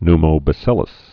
(nmō-bə-sĭləs, ny-)